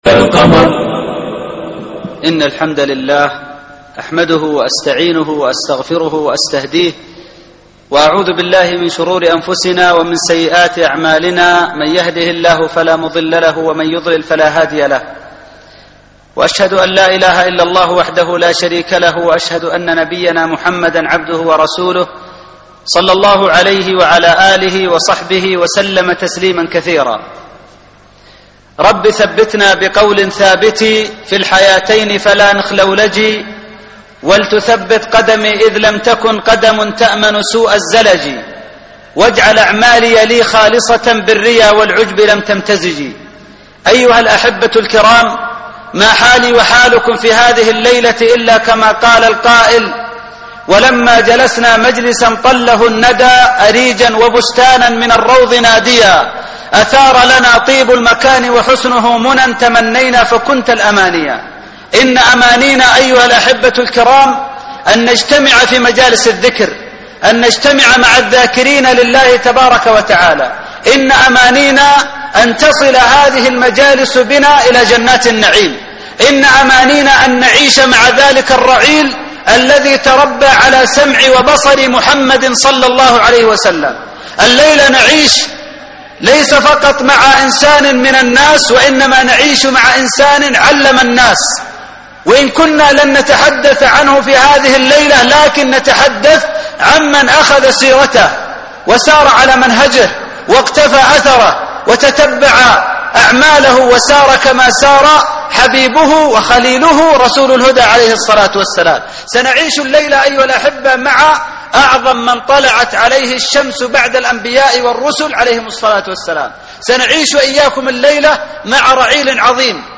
محاضرة اليوم